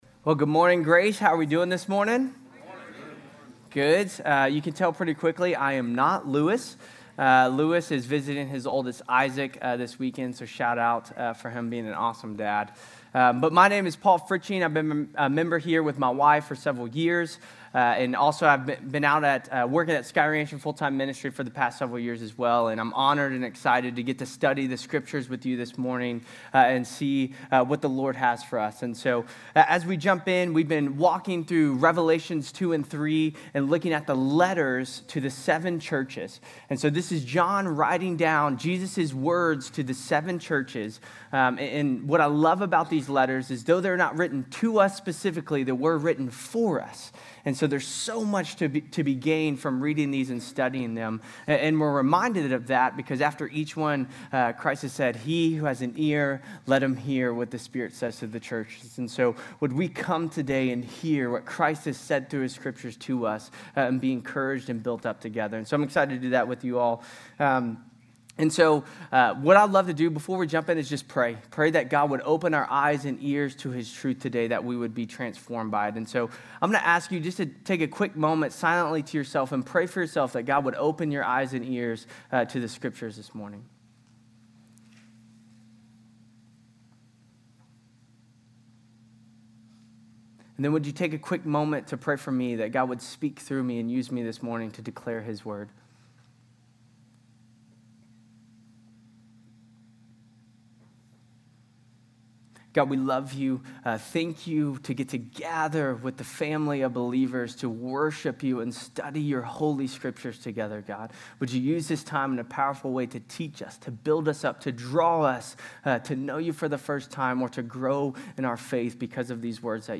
GCC-Lindale-September-25-Sermon.mp3